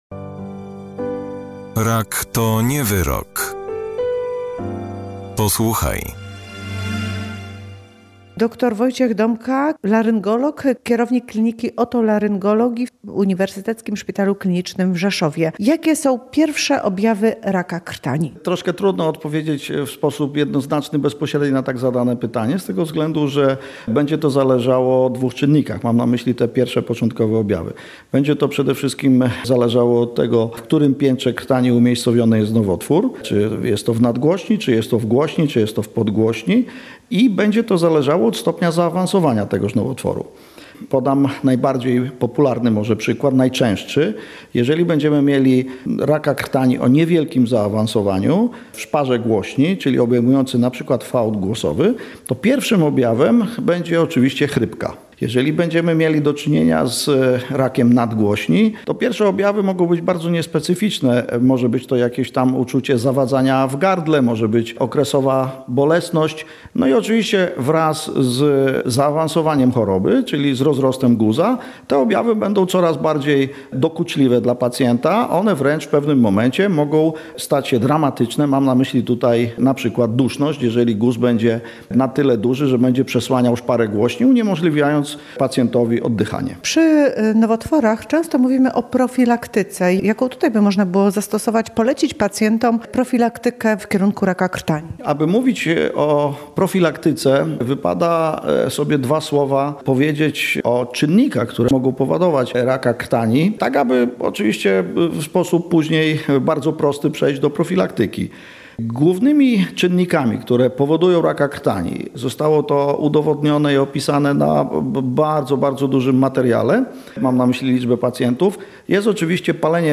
Więcej w rozmowie